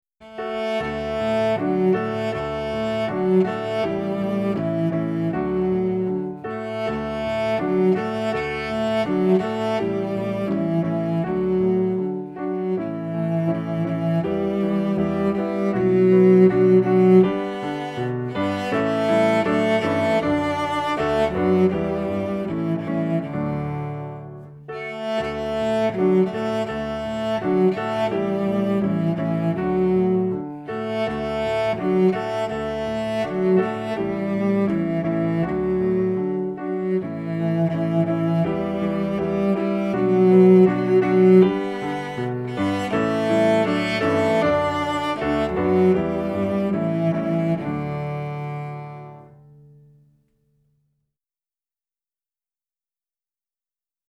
Nemška ljudska pesem